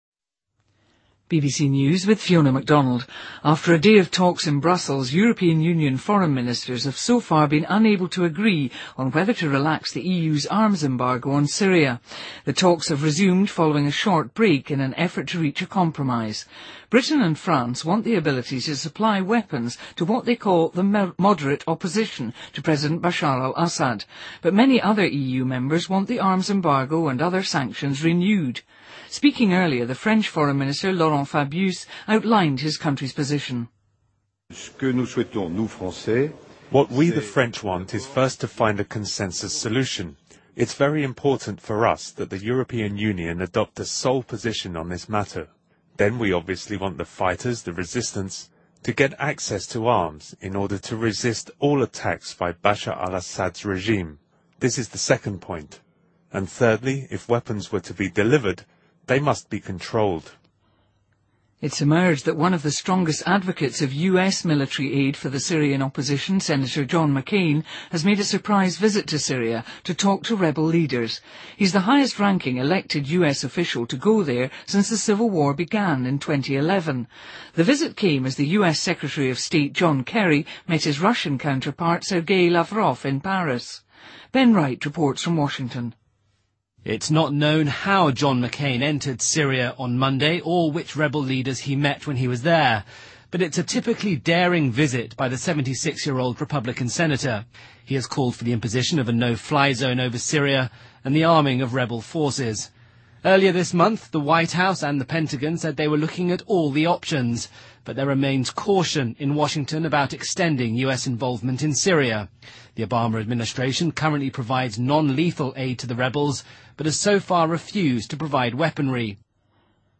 BBC news,2013-05-28